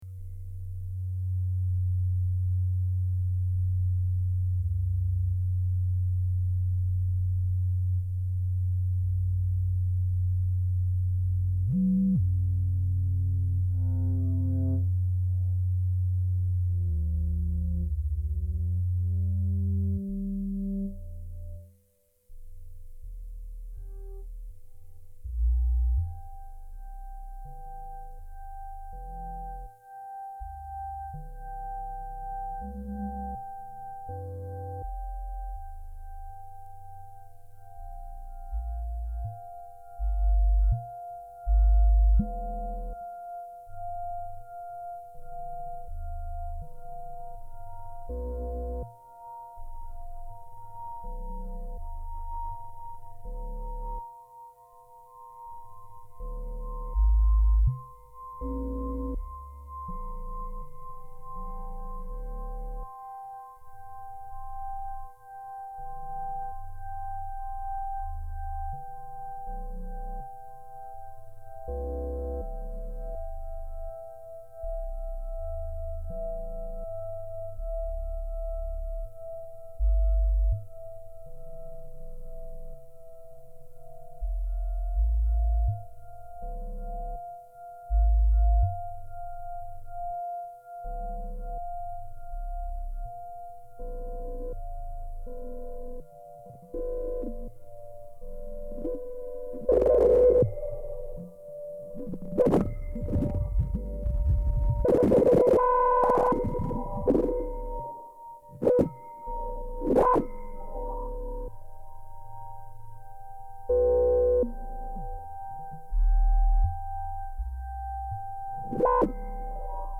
Please enjoy the dusty electronic noise produced herein.
I still got the guts of the experimental, improvising musician in me.
At this point, I have a secure home music studio that I can use 24/7.
Performed with an ARP Odyssey mk2 vintage, and Logic Studio instruments.